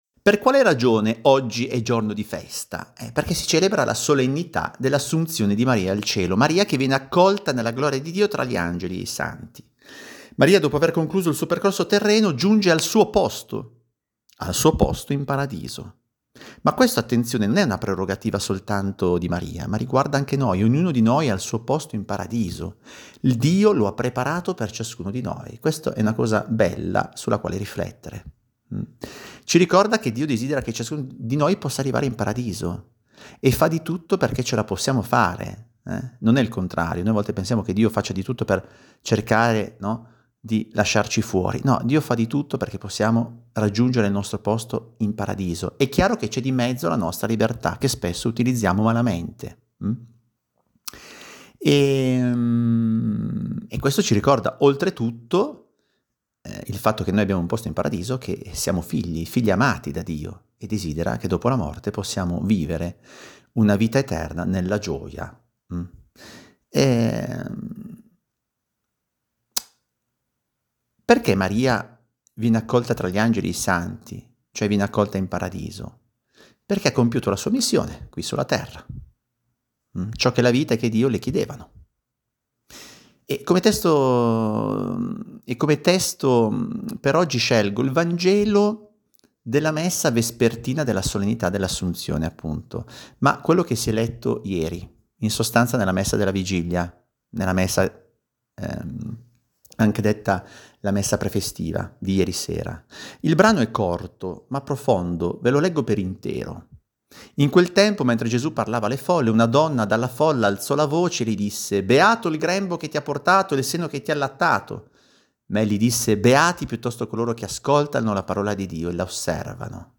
Di seguito il commento audio alla Lettura